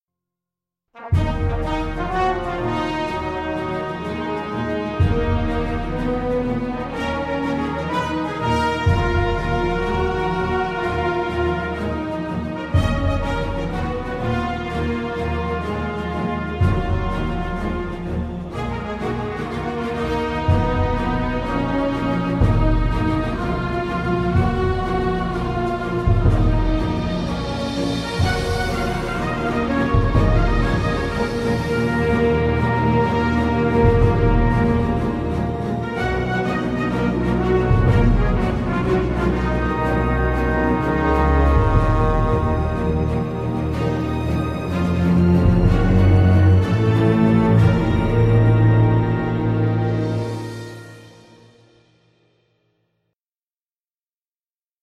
[Artist: Instrumental ]